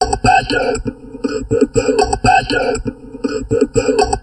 Index of /90_sSampleCDs/USB Soundscan vol.07 - Drum Loops Crazy Processed [AKAI] 1CD/Partition D/04-120TALK A